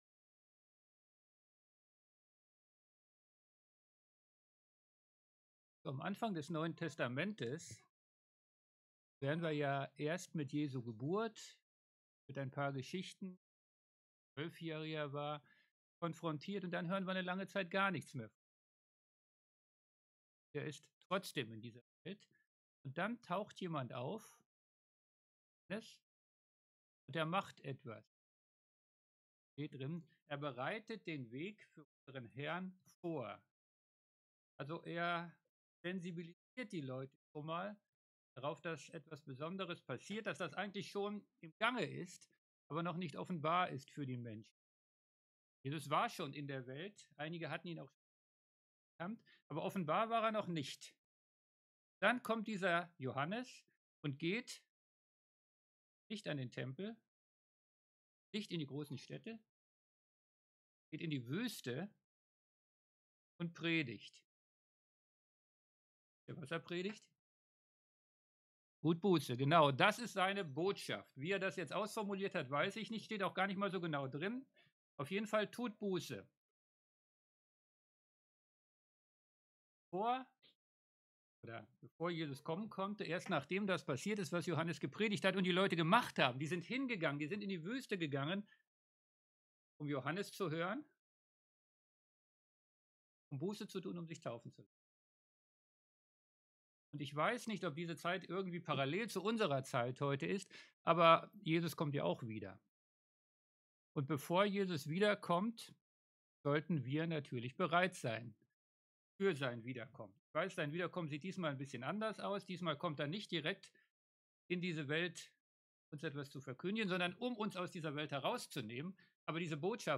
MP3 Predigten